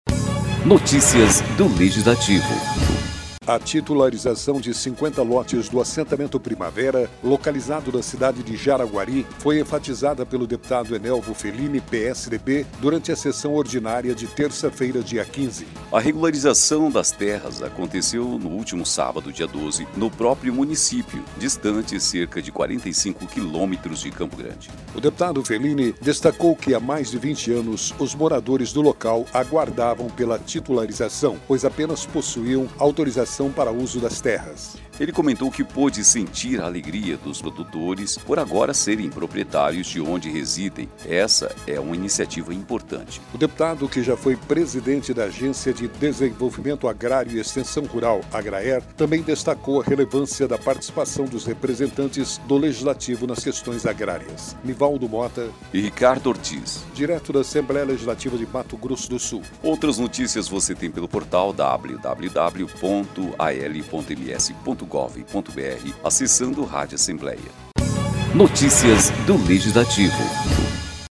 A titularização de 50 lotes do assentamento Primavera, localizado na cidade de Jaraguari, foi enfatizada pelo deputado Enelvo Felini (PSDB) durante a sessão ordinária desta terça-feira (15).